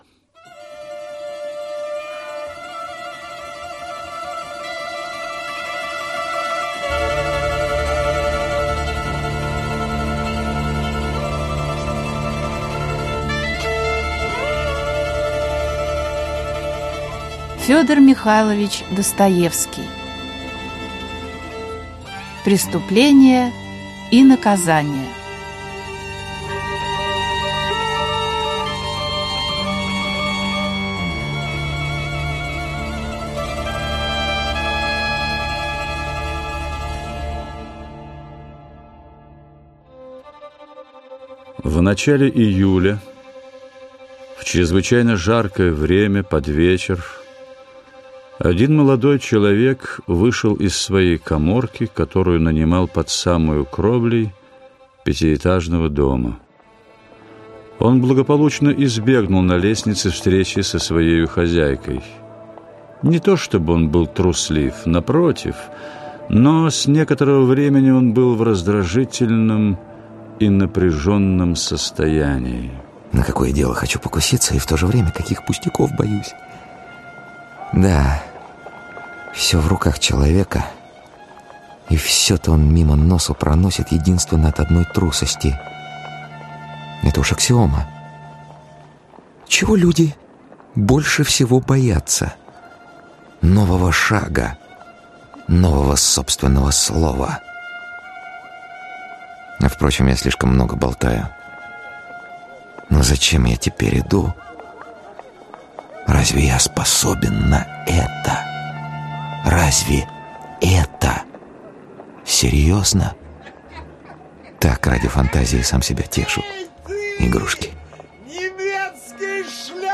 Аудиокнига Преступление и наказание (спектакль) | Библиотека аудиокниг